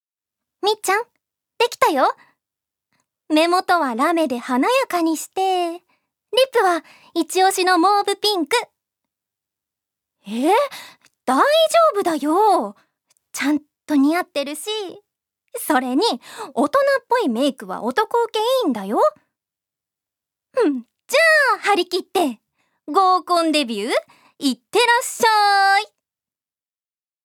ジュニア：女性
セリフ１